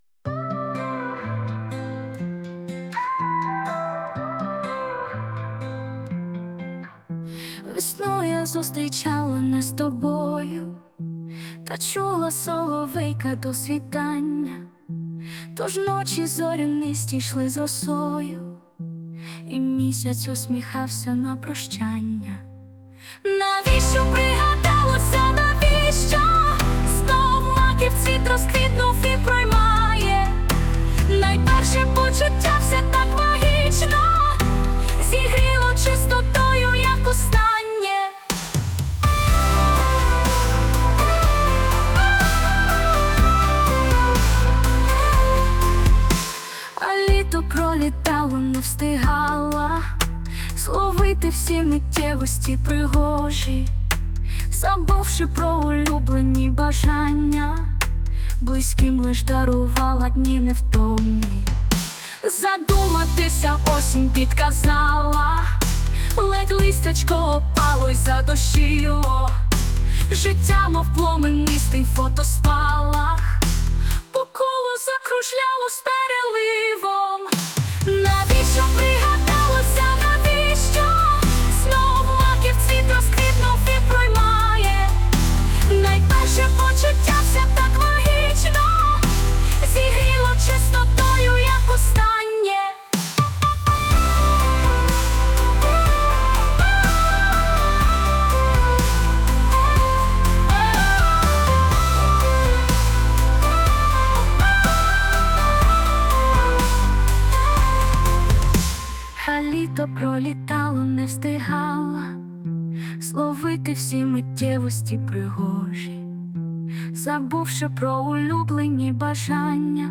Музична композиція створена за допомогою SUNO AI
СТИЛЬОВІ ЖАНРИ: Ліричний
Голос, слова, музика - ціла гармонія! 12 sp give_rose hi
Ох.., яка чудова музика і пісня...